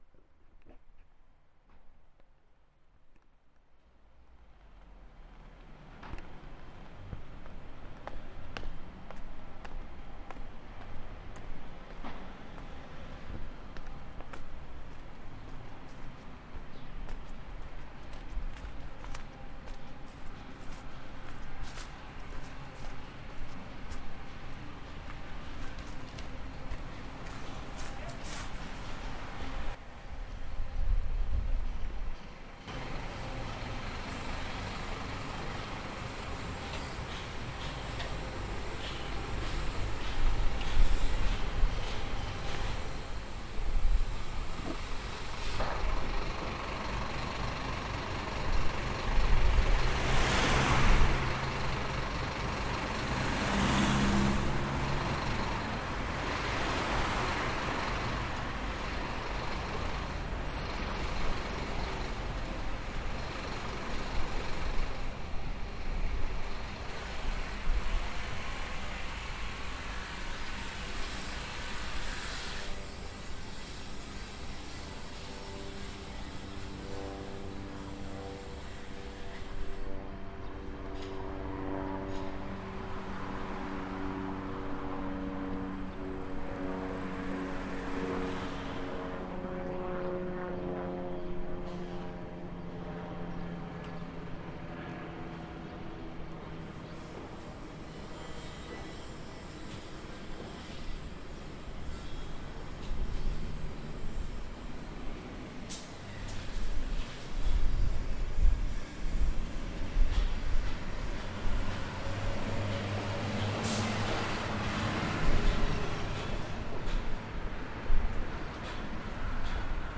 The whole block that used to consist of a pub, parking and some shops has been flattened and being re-build as inner city housing. The field recording captures me walking to the studio via this construction sight in full sonic flight then entering my studio, going up the stairs and settling in to do some work.